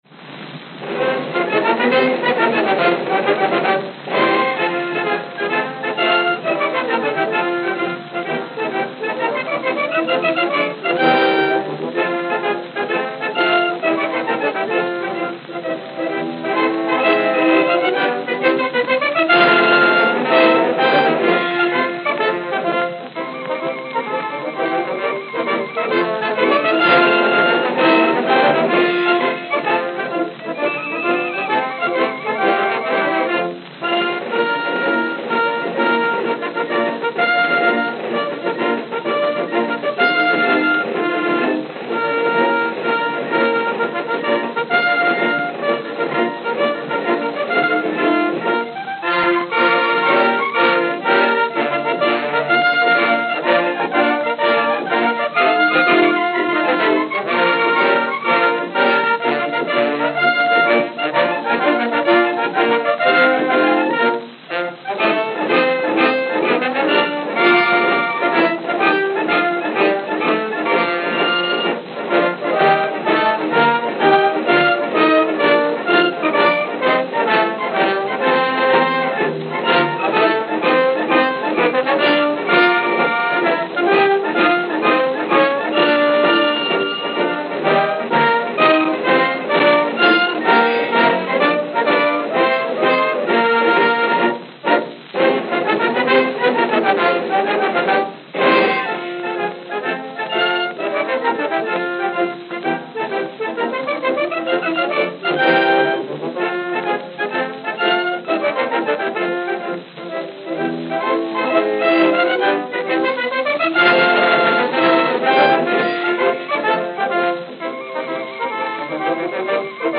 Edison Diamond Discs
Note: Worn.